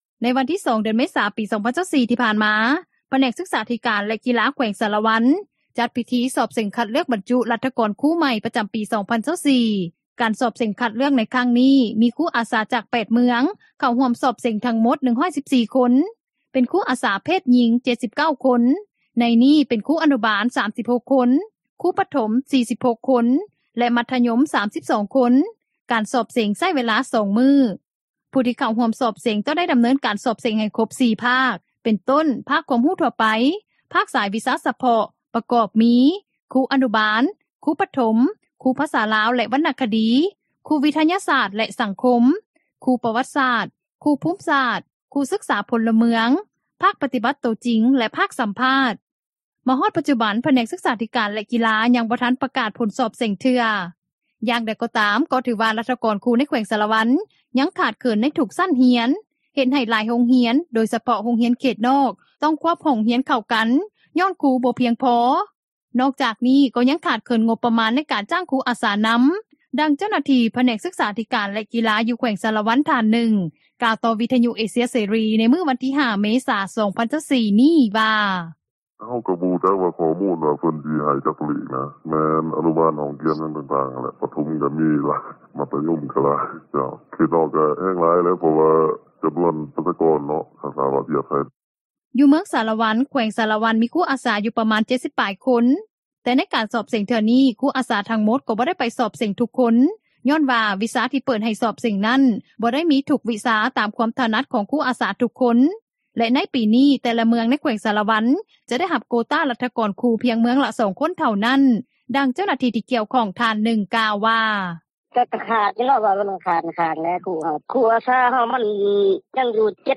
ປັດຈຸບັນ ຄູອາສາເຫຼົ່ານີ້ ຍັງບໍ່ມີເງິນເດືອນ ແຕ່ທາງພະແນກສຶກສາທິການ ແລະກິລາ ໃນຂັ້ນແຂວງ ກໍກໍາລັງຊອກຫາເງິນອຸດໜຸນ ໃຫ້ຄູອາສາເຫຼົ່ານີ້ຢູ່, ດັ່ງເຈົ້າໜ້າທີ່ ທີ່ກ່ຽວຂ້ອງ ນາງນຶ່ງ ກ່າວໃນມື້ດຽວກັນນີ້ວ່າ: